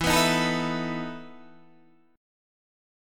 EM7sus4 Chord